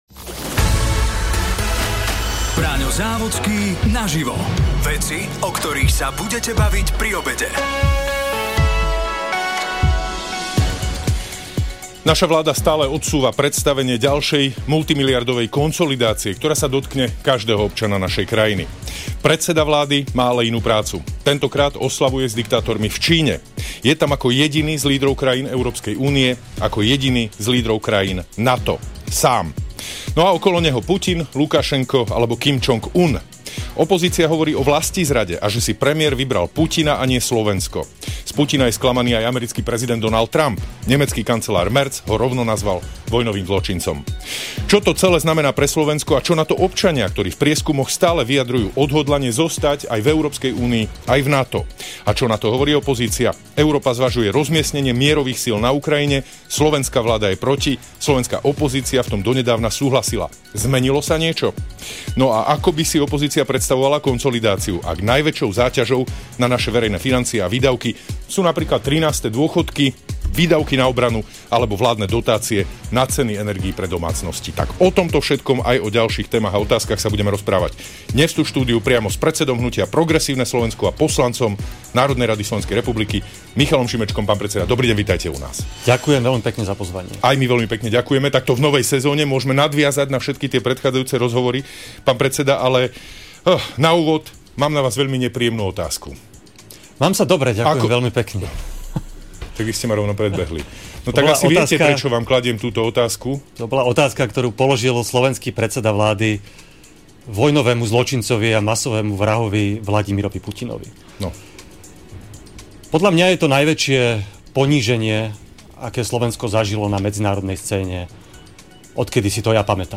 sa rozprával s predsedom poslaneckého klubu strany Smer – SD a predsedom sociálneho výboru parlamentu, exministrom práce Jánom Richterom a s poslancom parlamentu za SaS a členom sociálneho výboru Vladimírom Ledeckým.